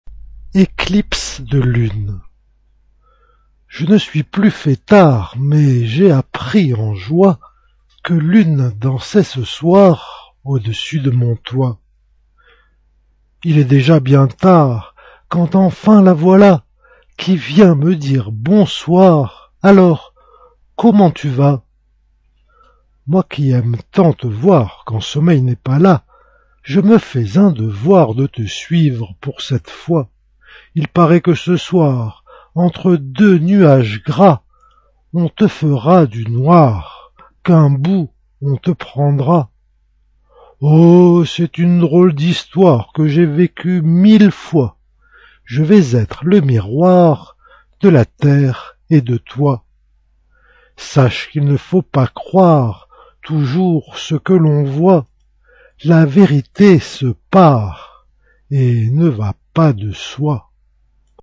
Une petite poésie pour cette éclipse.